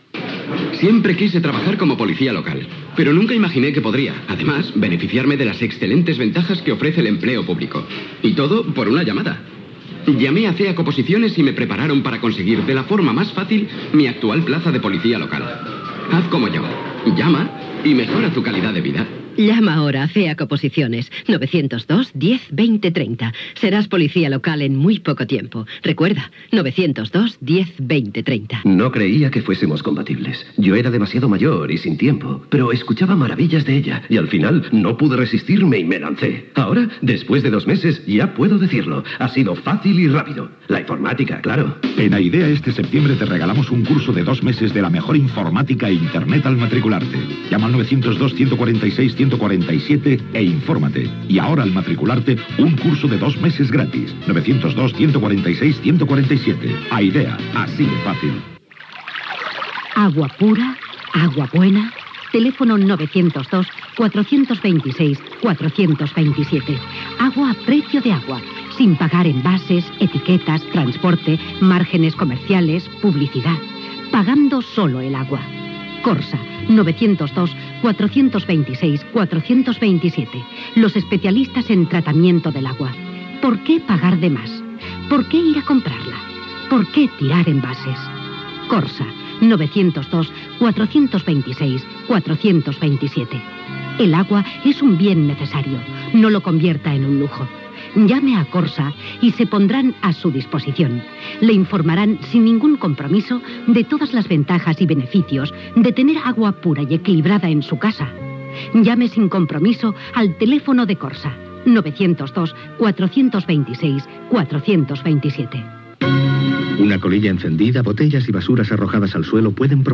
Publicitat, pàgina web de COPE, indicatiu de la cadena, oració de l'Àngelus. Careta de l'informatiu de COPE, informació de les dades de l'atur, investigació sobre l'agència de valors Gescartera, Ponferrada, reunió de parlaments autonòmics.
Gènere radiofònic Religió Informatiu